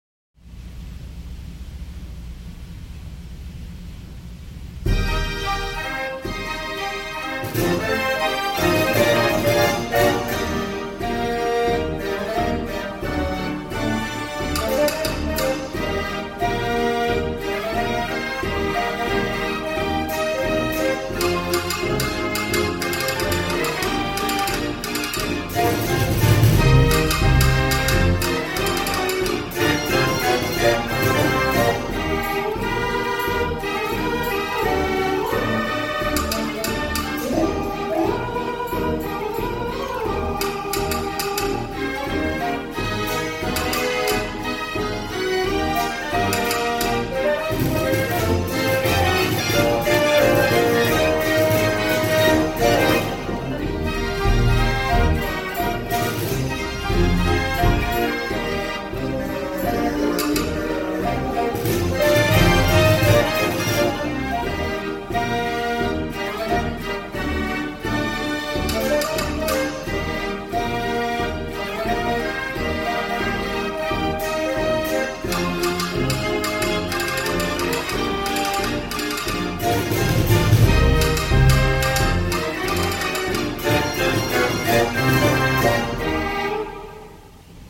Mortier 97 Key Dance organ 1924 - Stahls Automotive Collection
This was instrument was designed to supply dance music in European dance halls, with a heavy beat and loud volume!
Mortier-Dance-Organ.mp3